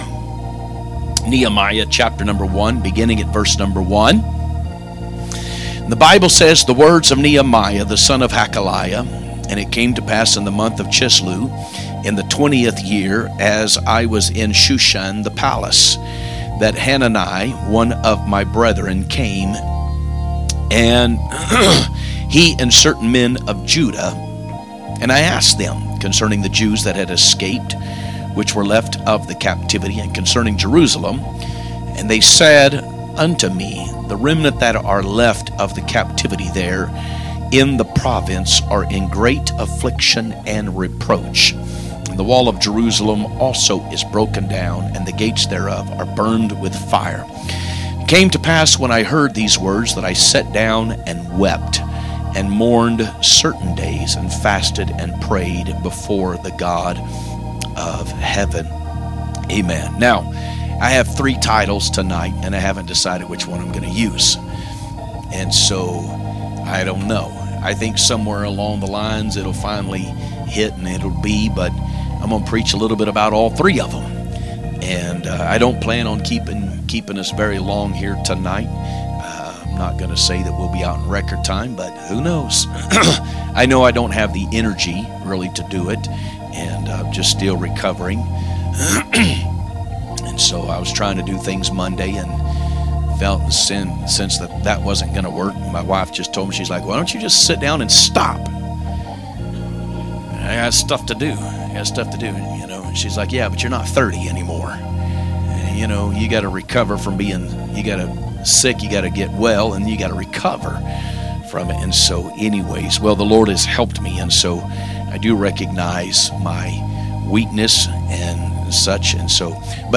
From Series: "2025 Preaching"